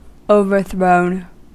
Ääntäminen
Ääntäminen US US : IPA : /oʊ.vɚ.θɹoʊn/ Haettu sana löytyi näillä lähdekielillä: englanti Käännöksiä ei löytynyt valitulle kohdekielelle.